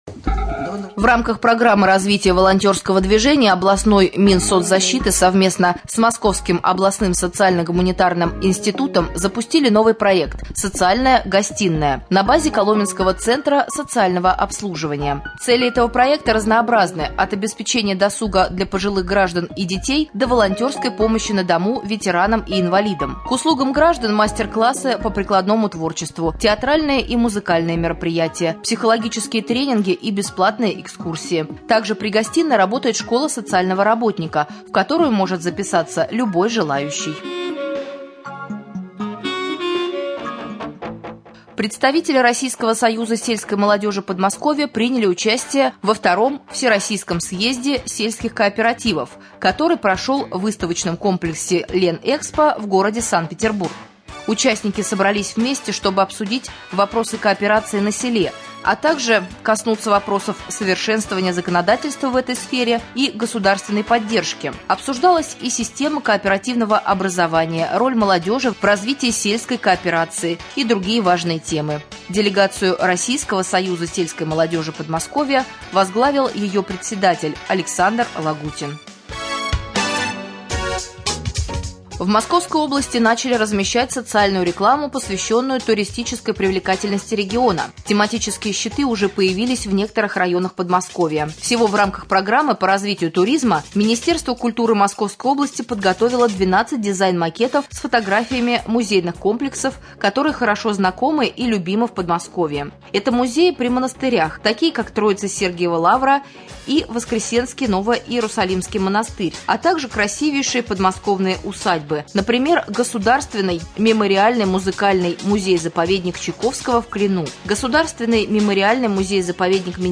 23.04.2014г. в эфире раменского радио
2.Новости.mp3